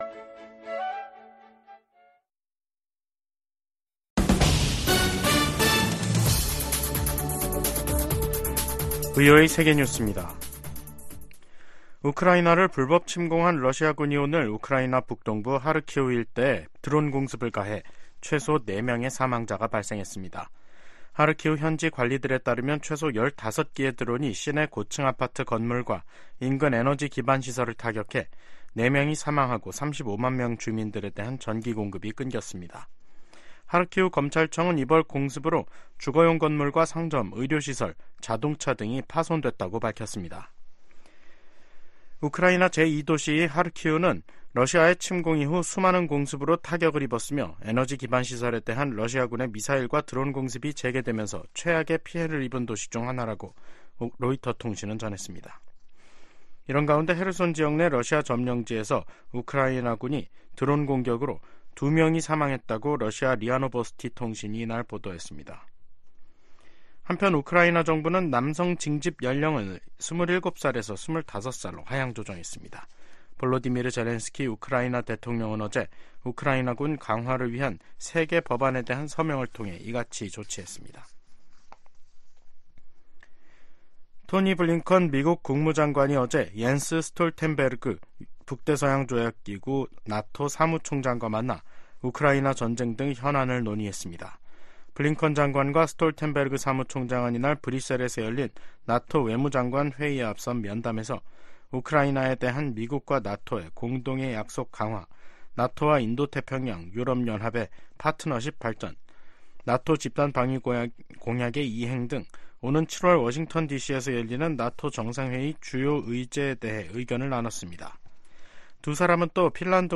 VOA 한국어 간판 뉴스 프로그램 '뉴스 투데이', 2024년 4월 4일 2부 방송입니다. 미국 백악관과 국무부는 다음 주 워싱턴에서 열리는 미일 정상회담에서 북한 문제와 미한일 3국 협력 방안 등이 주요 의제가 될 것이라고 밝혔습니다. 미 국방부는 최근 실시된 미한일 3국 연합공중훈련에 대해 3국 협력의 힘을 보여주는 것이라고 평가했습니다.